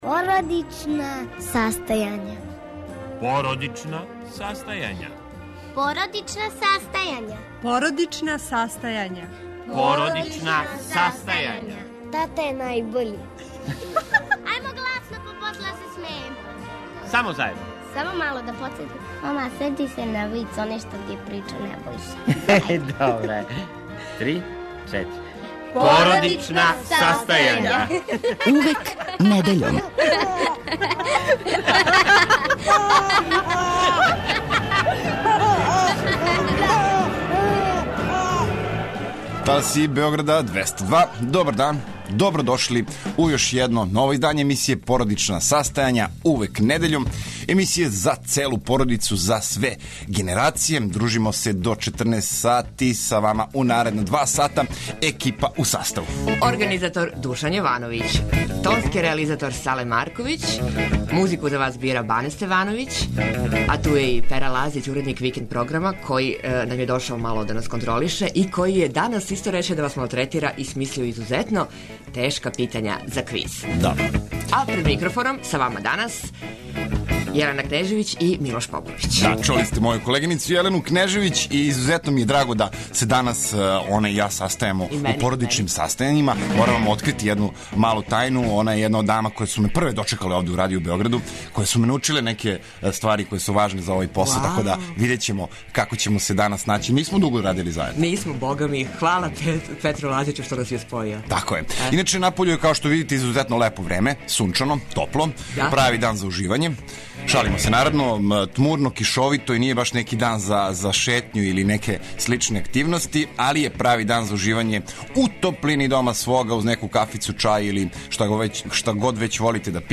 Од чланова хора Viva Vox чућете рецепт за јело коме ће се веселити ваша непца - пилетину у бешамел сосу са наренданим качкаваљем. Причаћемо о једном од најомиљенијих аутомобила у бившој Југославији, чувеном Фићи, који слави 61. рођендан.